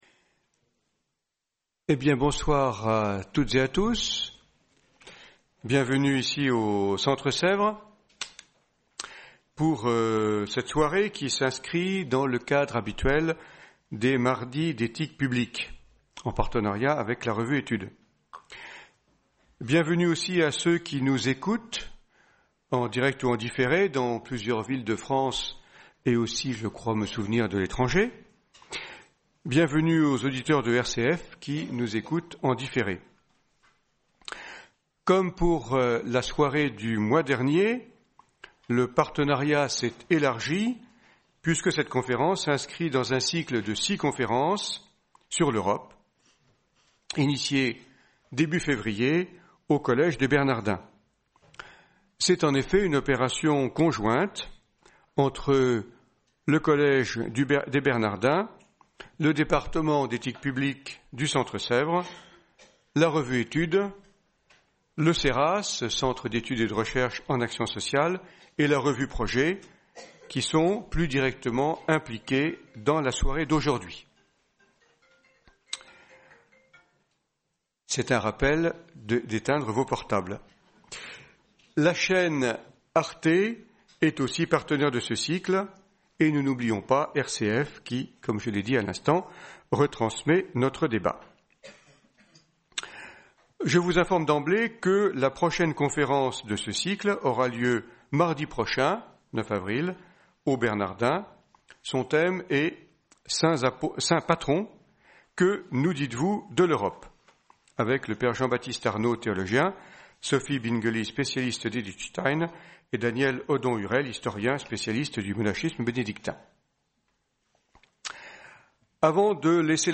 Soirée mardi d’éthique publique du 2 avril 2019, dans le cadre d’un cycle de 6 conférences sur le thème » L’Europe face aux Européens« , organisé en partenariat avec le Collège des Bernardins, les revues Études et Projet ainsi qu’Arte .